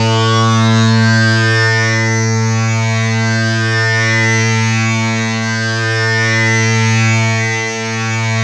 Index of /90_sSampleCDs/Sound & Vision - Gigapack I CD 2 (Roland)/SYN_ANALOG 1/SYN_Analog 2